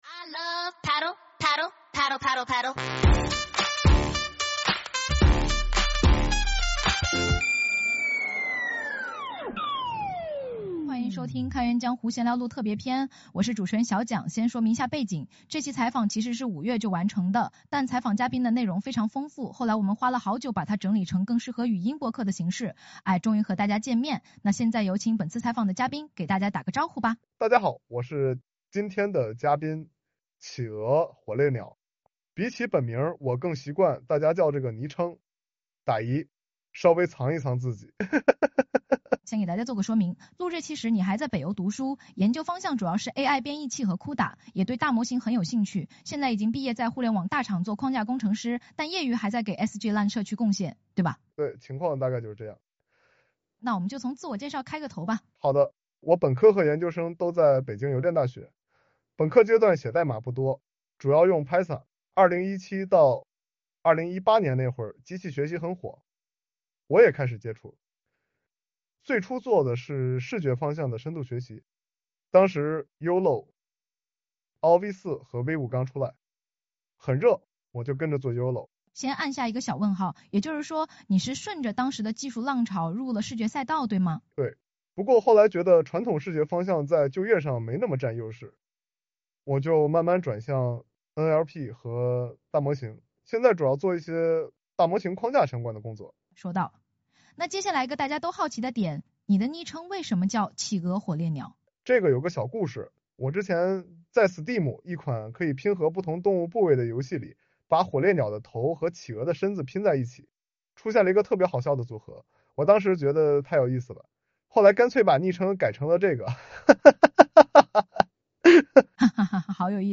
让 AI 主理人小桨捧读这一段江湖故事，边走边听，也能和我们一起感受🦩的热情。